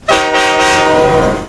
TR-HORN.WAV